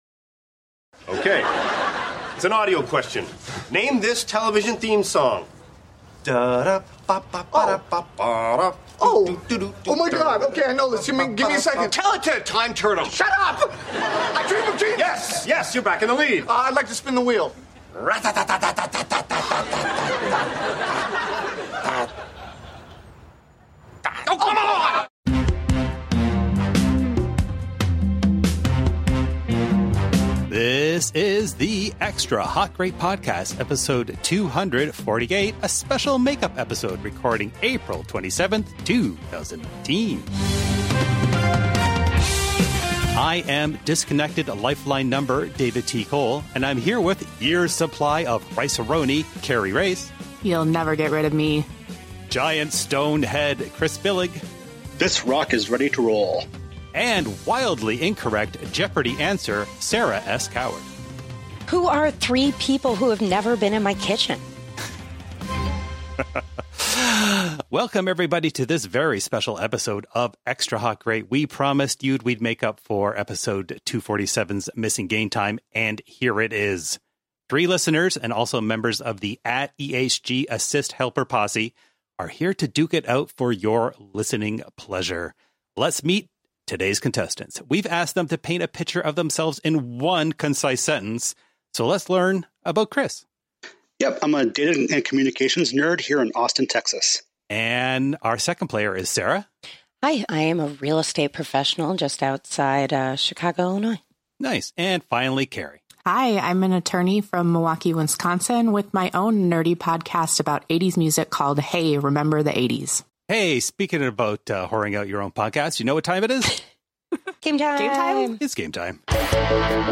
We played TV themes, they tried to figure out what show they were from.